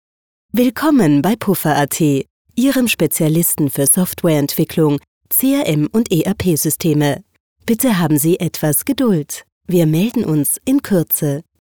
Professionelle Mobilboxansage mit/ohne Musikmischung
Beispiel 1: Mailboxansage
mobilboxansagen_example04.mp3